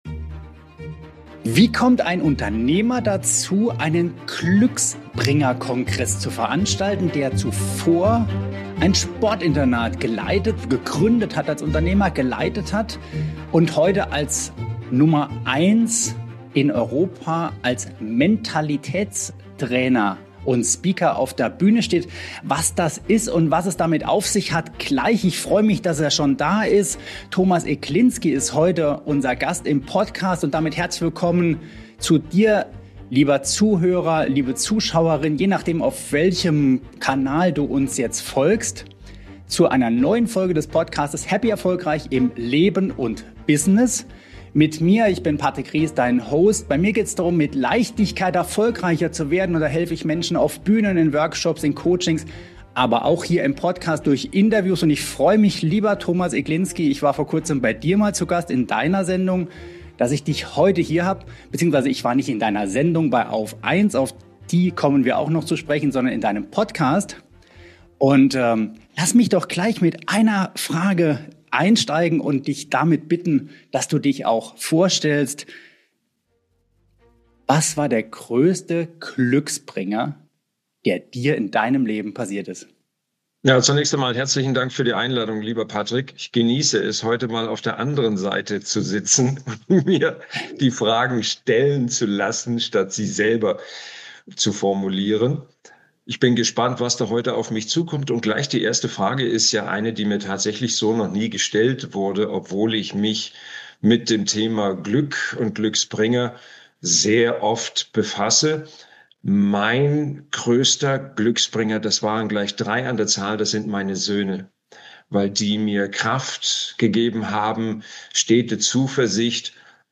Ein tiefgründiges und zugleich motivierendes Gespräch mit einem außergewöhnlichen Gast – für alle, die erfolgreicher, bewusster und erfüllter leben wollen.